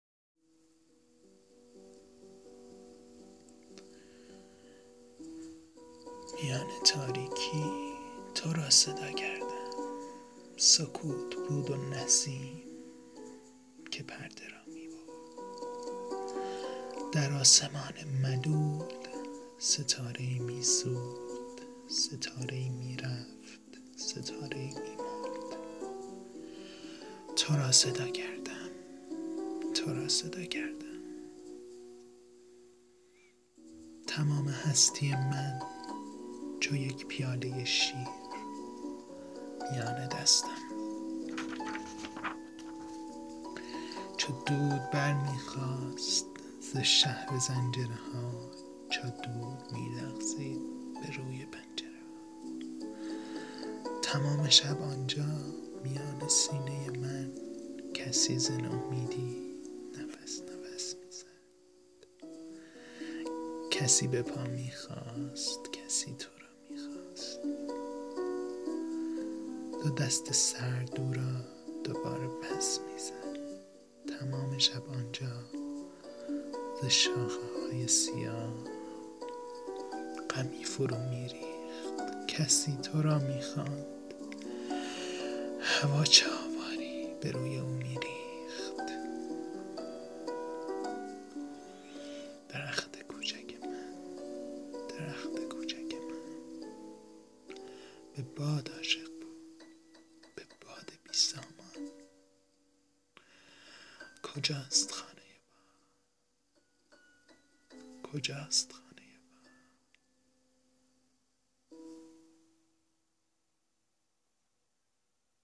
برای شما عزیزان شعر "میان تاریکی" از "فروغ فرخزاد" را به صورت صوتی در آوردم با اندک امکانات و محدودیت هایی که داشتم .
اشاره ریزی هم بزنم که اصلا و ابدا صدام خوب نیست.
صدای گرم و قشنگی داری (عمیق و گیراست)
نوای پیانو ی پس زمینه هم با وجود سادگی با شعر مچ مچ بود
و یه مورد دیگه اینکه (با جود علاقه شدید خودم به این مسئله ) صدای عوض کردن صفحه برای فایل صوتی با زمان کوتاه زیاد جالب نیست
مرسی از دقت و نکته سنجی شما و نظر دلگرم کنندتون، بله درست فهمیدید، تمام اتفاقات این دکلمه توسط یک مایک رکورد شده و صدای عوض شدن صفحه نیز ناشی از همینه و خودمم اتفاقا با شما هم نظرم که این صدا نبود خیلی بهتر بود.